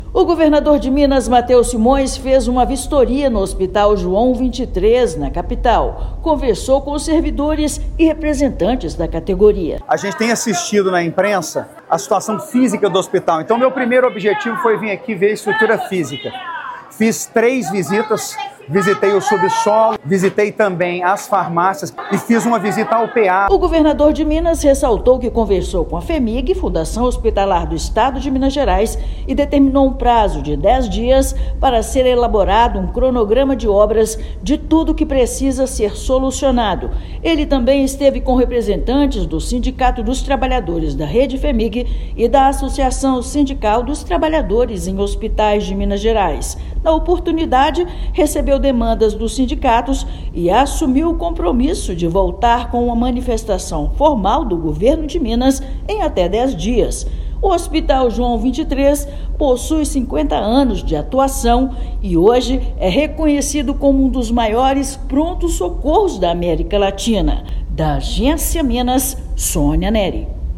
[RÁDIO] Governo de Minas vistoria Hospital João XXIII e determina cronograma de obras estruturais
Chefe do Executivo estadual conversou com direção da unidade de saúde e representantes dos servidores em Belo Horizonte. Ouça matéria de rádio.